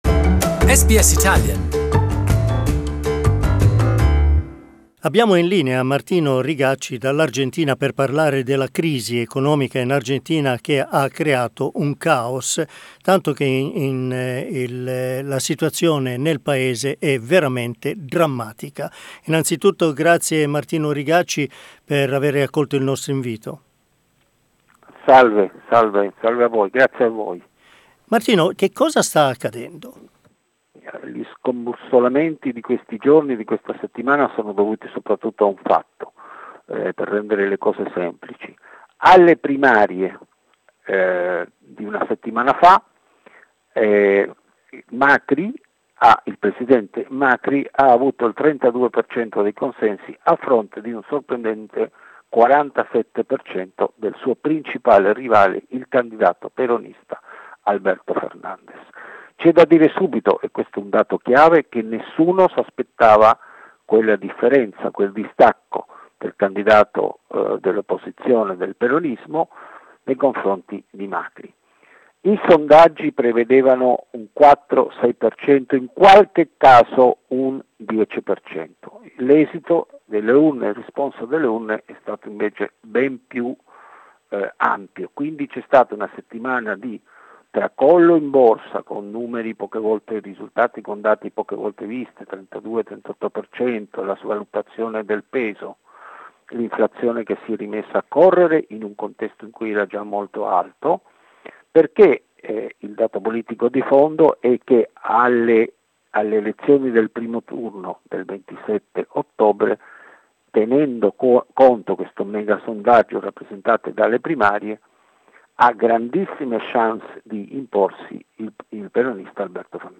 South American correspondent